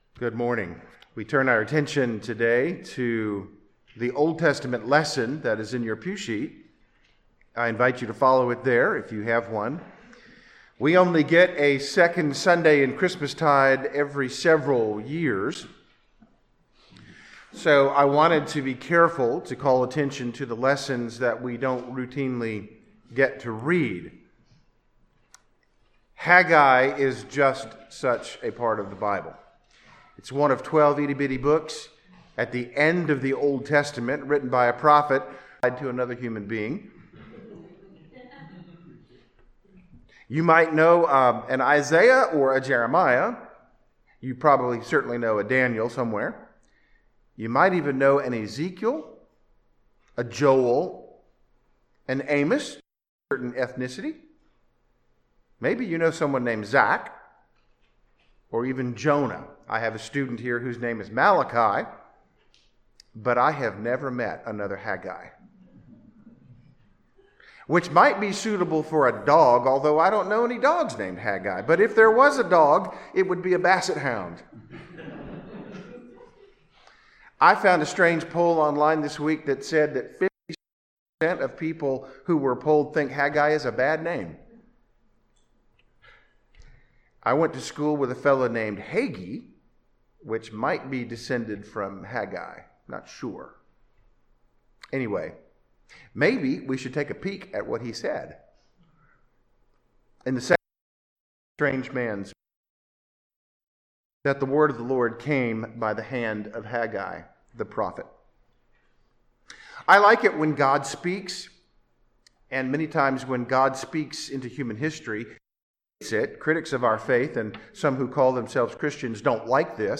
Sermons by All Saints Church, Lynchburg, Virginia (Anglican/Reformed Episcopal) “What's Shakin'?”